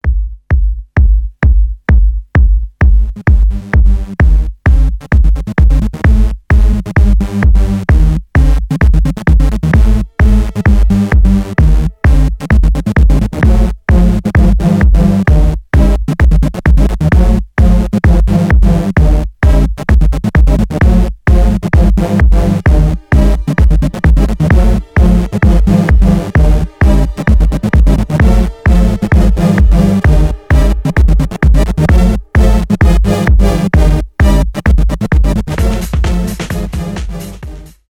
breakbeat , техно , басы , ритмичные
клубные , электронные